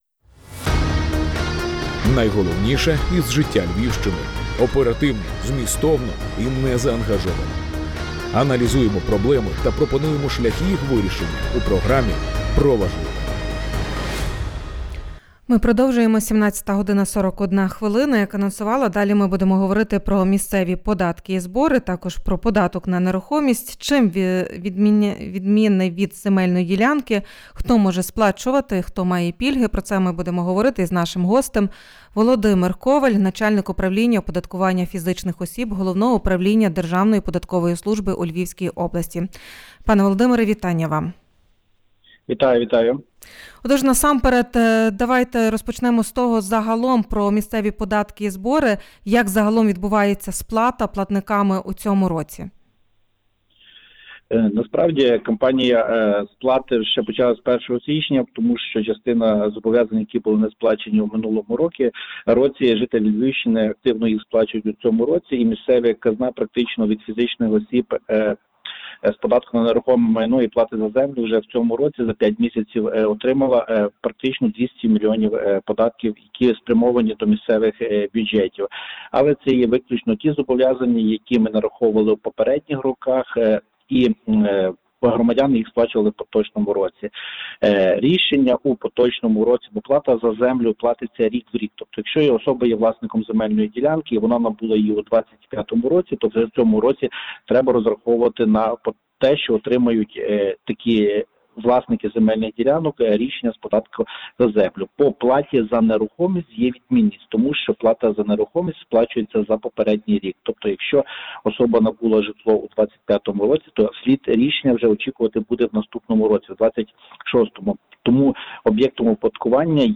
«Прямий ефір» на радіо: у центрі уваги – місцеві податки і збори